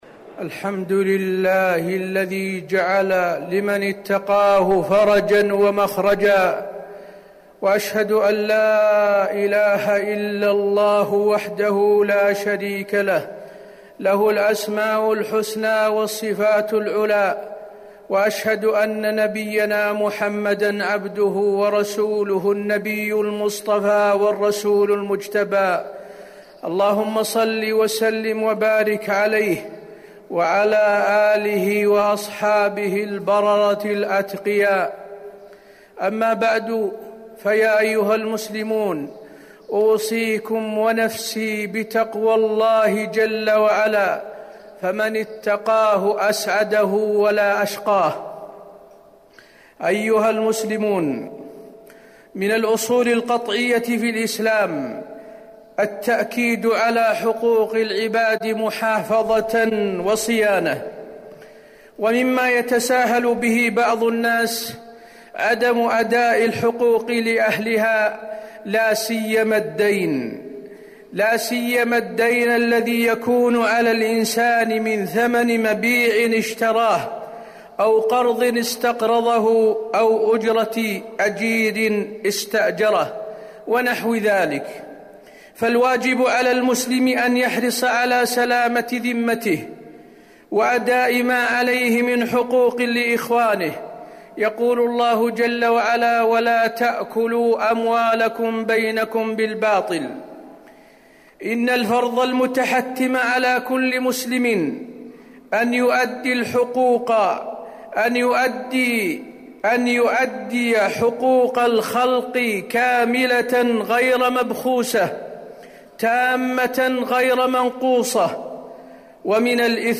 تاريخ النشر ٢٤ جمادى الآخرة ١٤٤٠ هـ المكان: المسجد النبوي الشيخ: فضيلة الشيخ د. حسين بن عبدالعزيز آل الشيخ فضيلة الشيخ د. حسين بن عبدالعزيز آل الشيخ الحرص على سلامة الذمة وأداء الحقوق